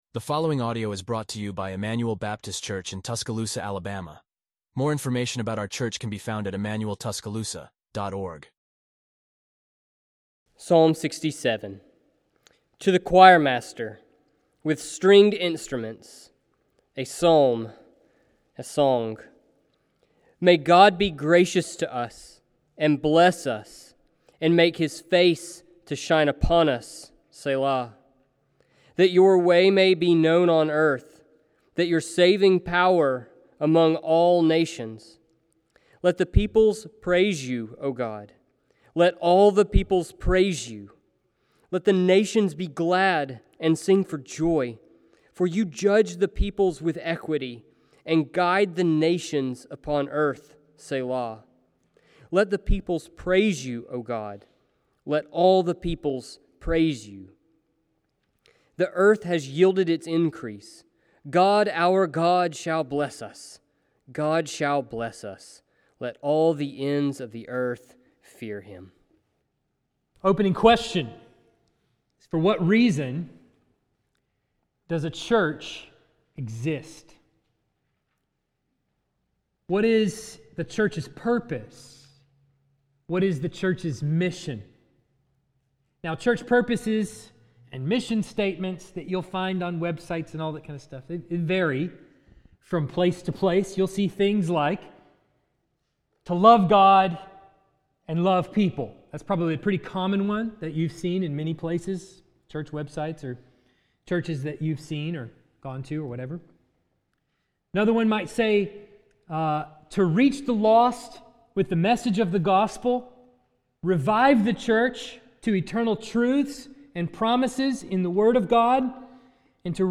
Expository Sermons from Emmanuel Baptist Church in Tuscaloosa, Alabama